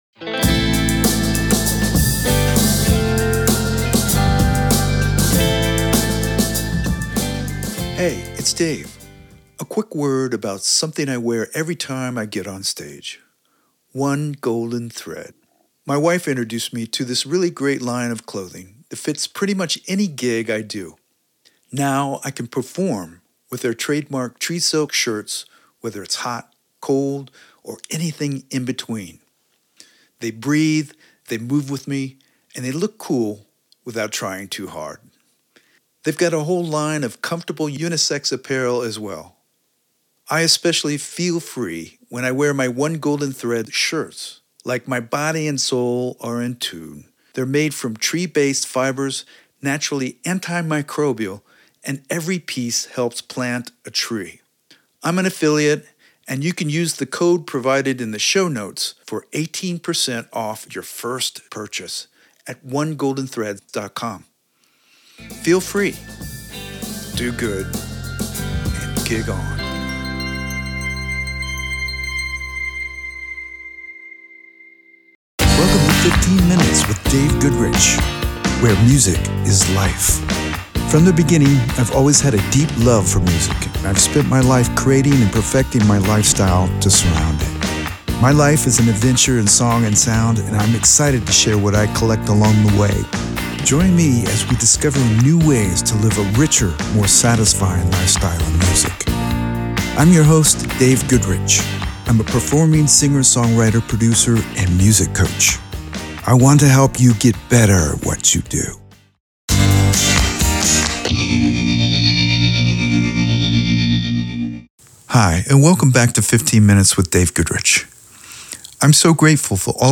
As a bonus, I have included my song Feel Free at the end of the episode.